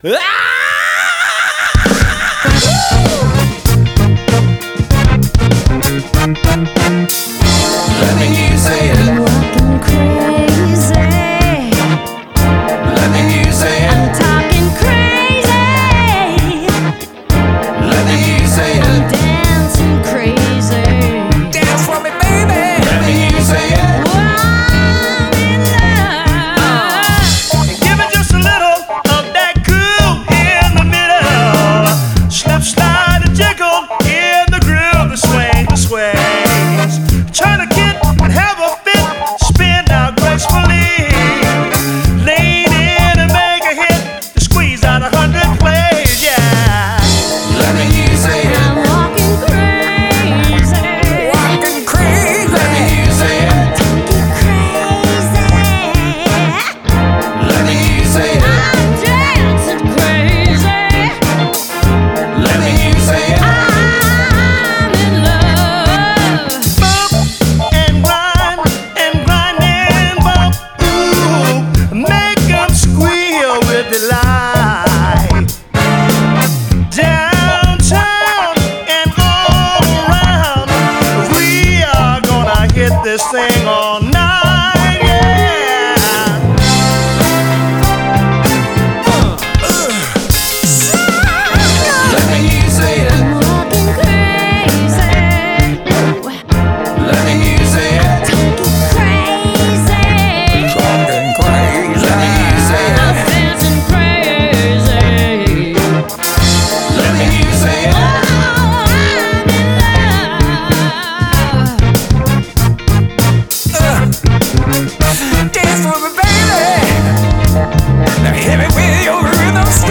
Puttin' the FUN in Funky.
Keys, horns and Glockenspiel